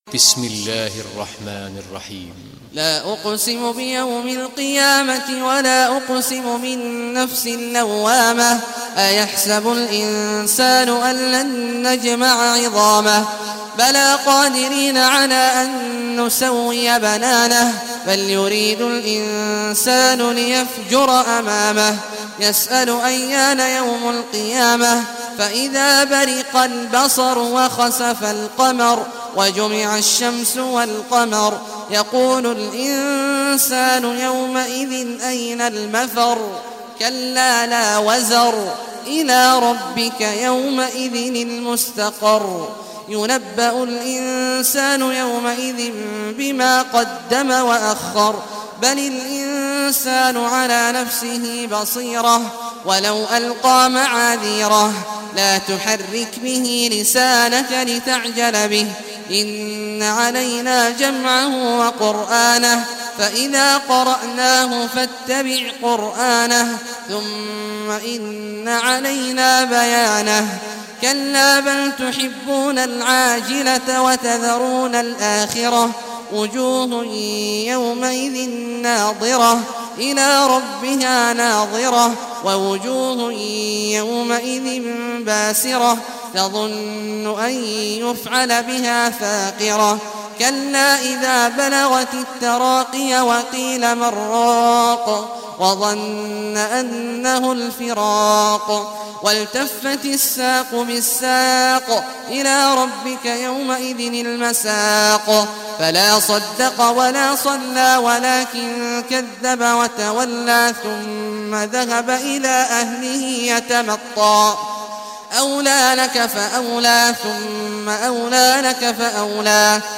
Surah Al-Qiyamah Recitation by Sheikh Al Juhany
Surah Al-Qiyamah, listen or play online mp3 tilawat / recitation in Arabic in the beautiful voice of Sheikh Abdullah Awad al Juhany.